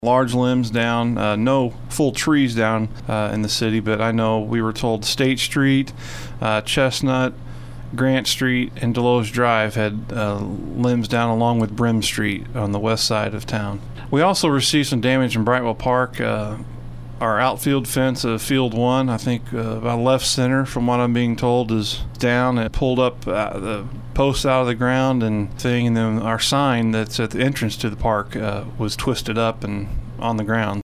Desloge City Administrator, Dan Bryan, says they had serious road blockage this morning on several streets and damage at Brightwell Park.